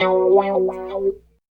28 GUIT 1 -R.wav